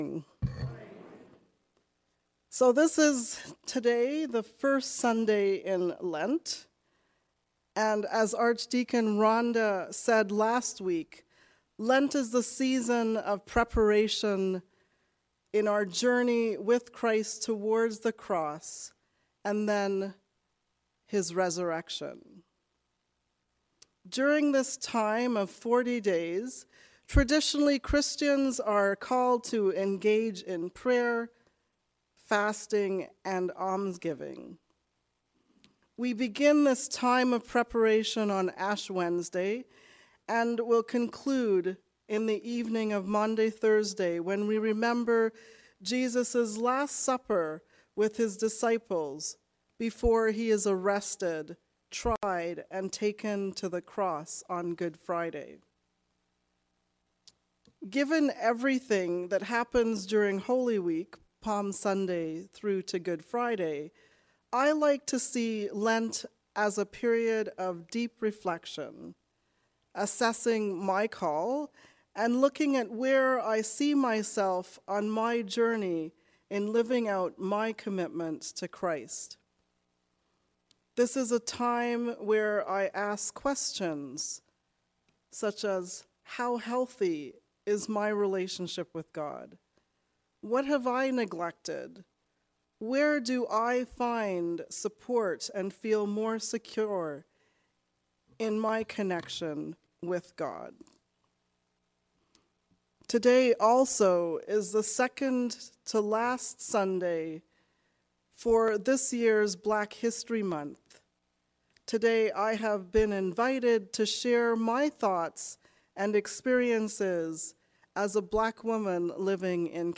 Beloved by God. A sermon on the First Sunday in Lent which is also the third Sunday in Black History Month.